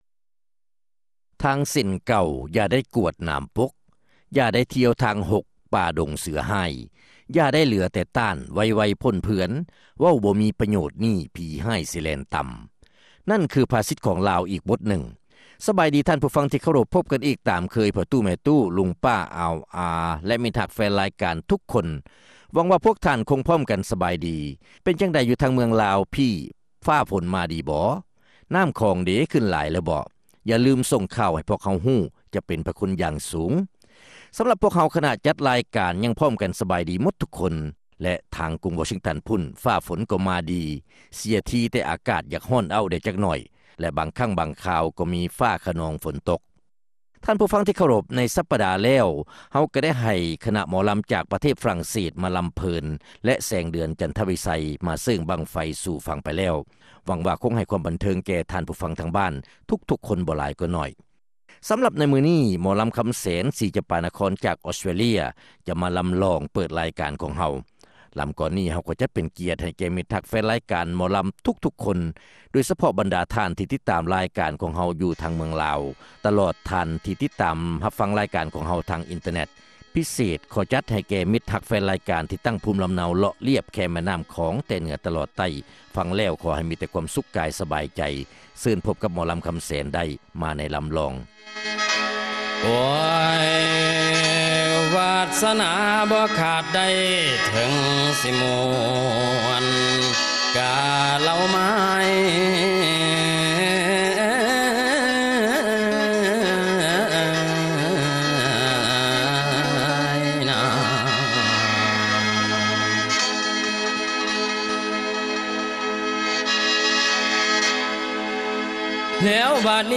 ຣາຍການໝໍລຳປະຈຳສັປະດາຂອງເດືອນມິຖຸນາ ວັນທີ 10 ປີ 2005